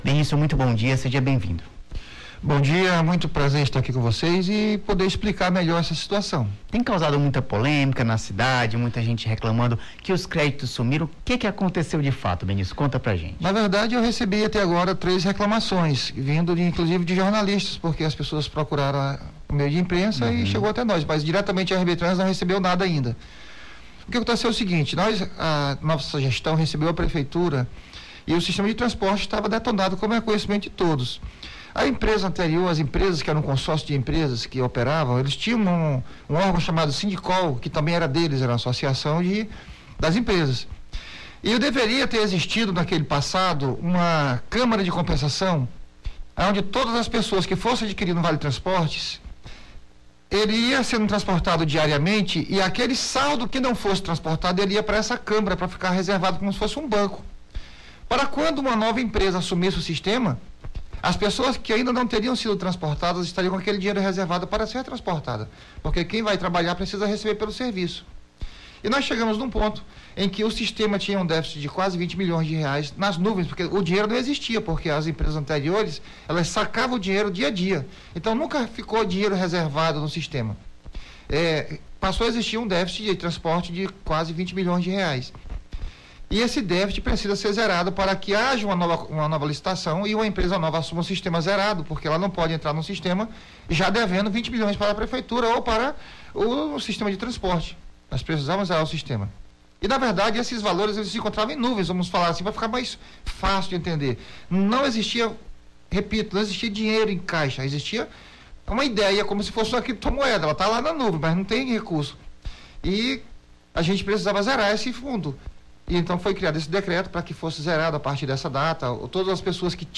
Em entrevista ao Jornal da Manhã desta quinta-feira (06), o Superintendente do RB Trans, Benício Dias, apresentou a posição da autarquia sobre o decreto publicado em 02 de janeiro que alterou o destino dos créditos inseridos em cartões de vale-transporte e passe estudantil.
Nome do Artista - CENSURA - ENTREVISTA (MUDANÇA SISTEMA BILHETAGEM) 06-04-23.mp3